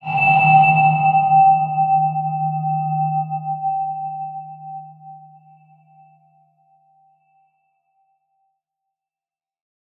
X_BasicBells-D#1-mf.wav